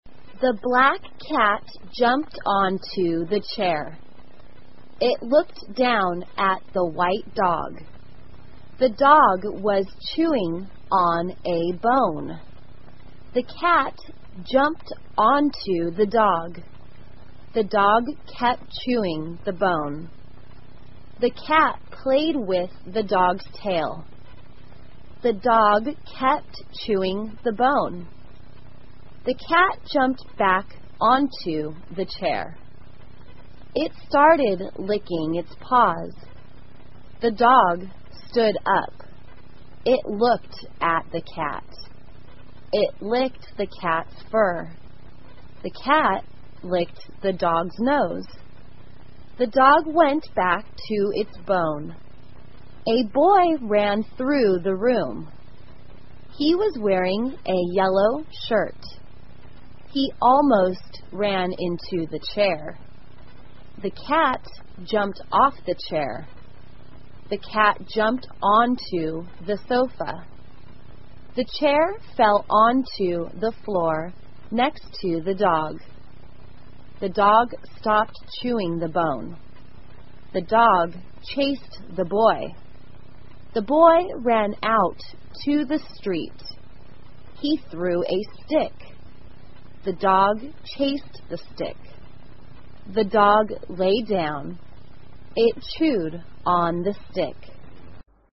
慢速英语短文听力 一只猫和一只狗 听力文件下载—在线英语听力室